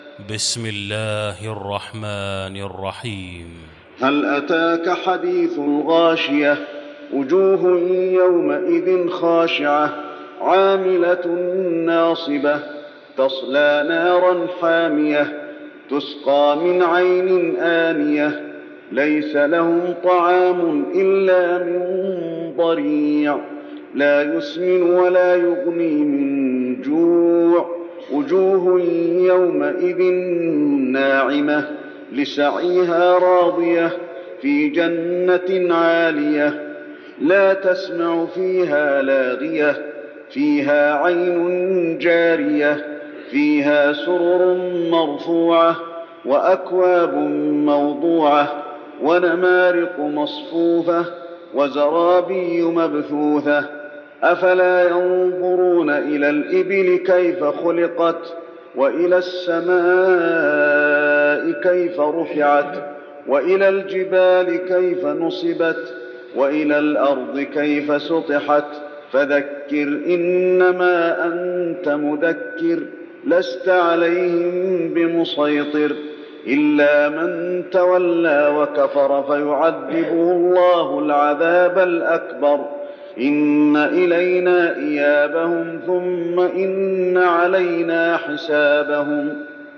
المكان: المسجد النبوي الغاشية